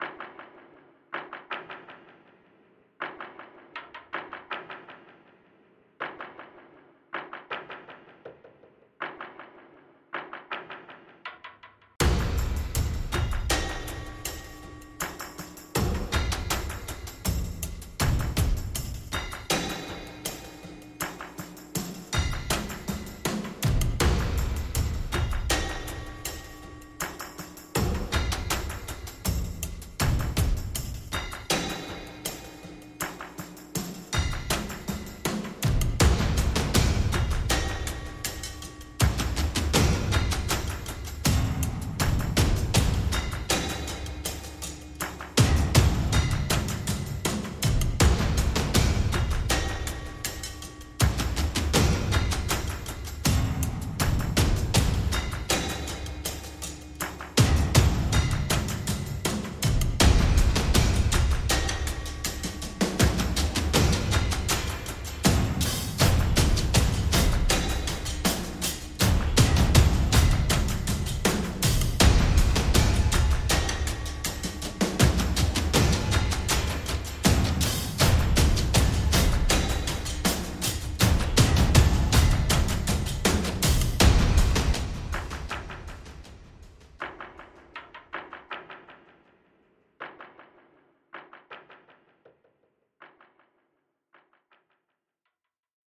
Музыка для глубоких раздумий